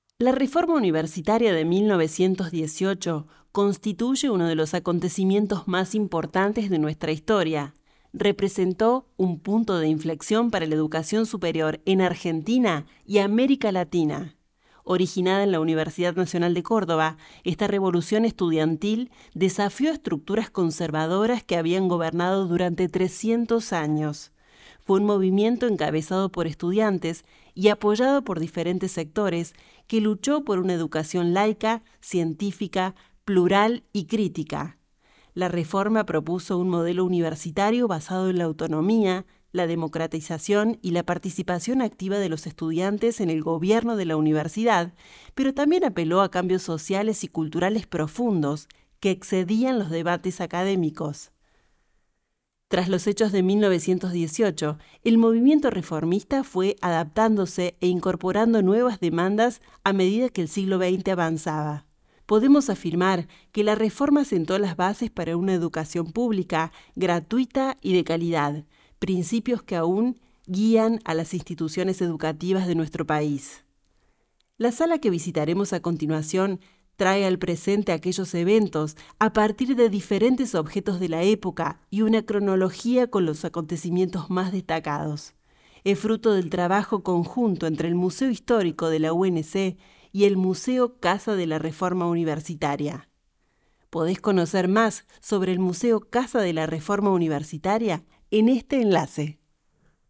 [Audioguía]